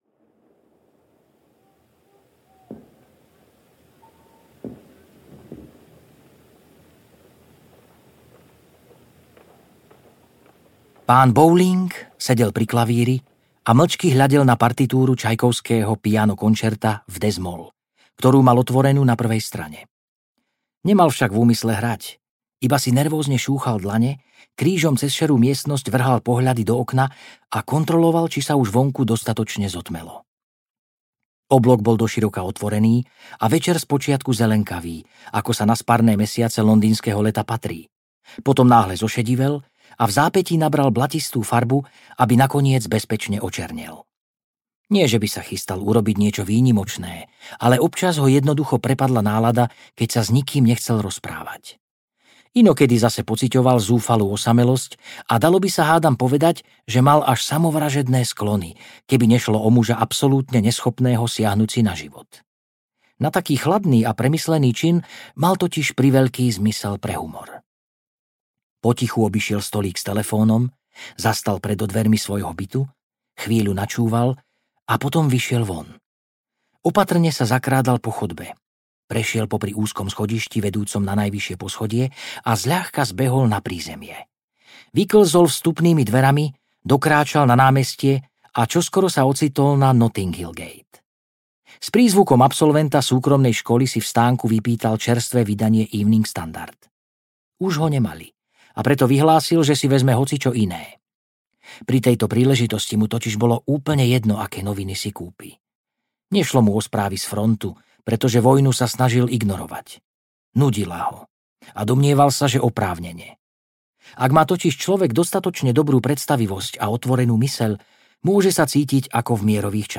Pán Bowling si kupuje noviny audiokniha
Ukázka z knihy